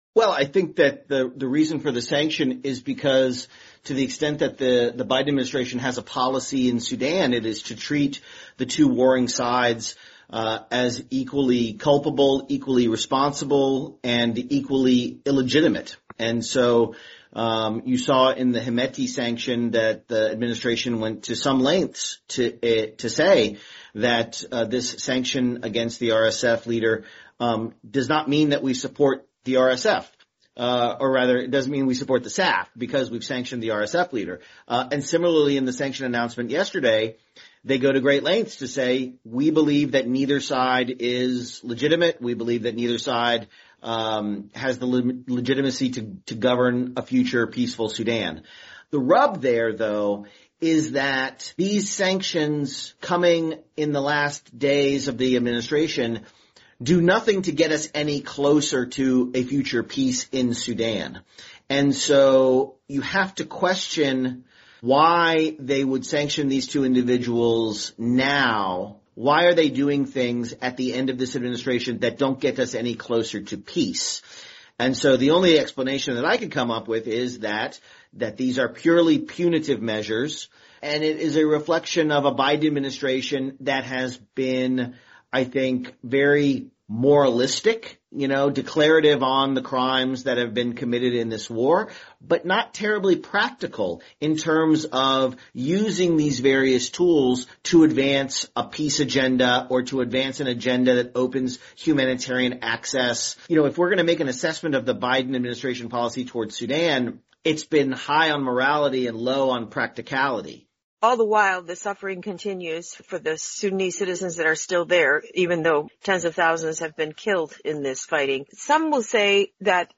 Sudan analyst assesses why Biden administration decided to sanction Sudanese Army leader now